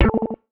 beep_21.wav